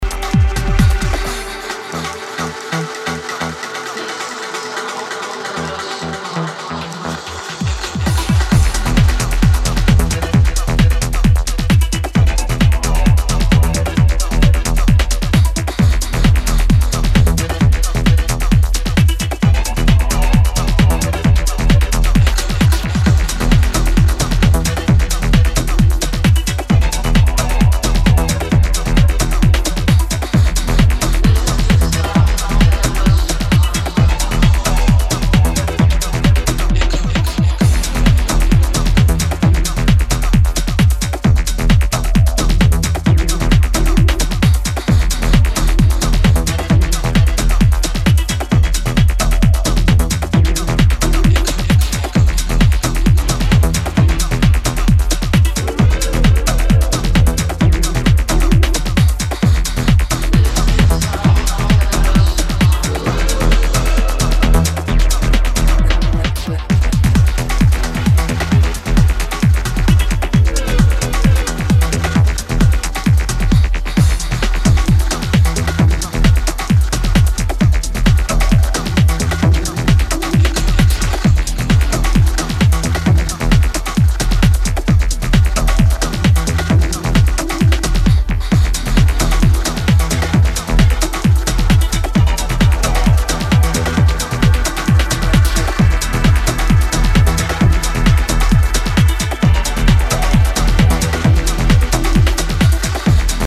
driving techno